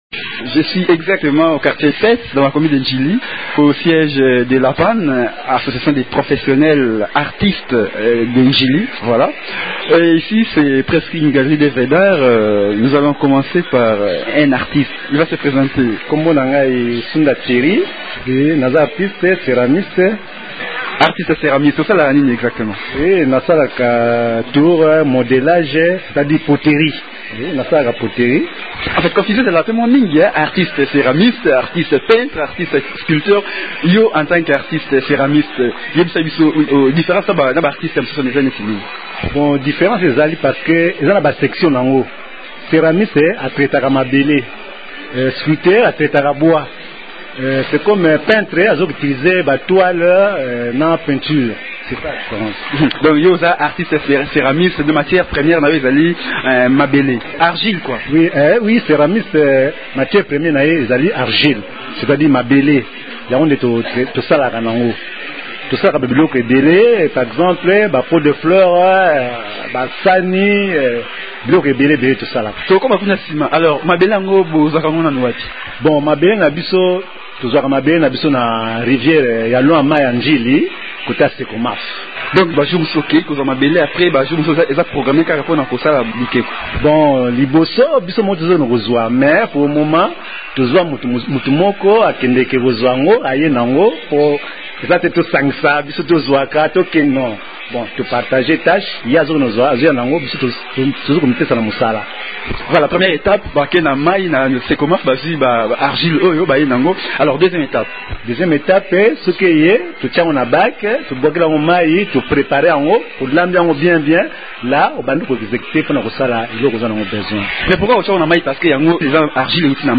Dans son atelier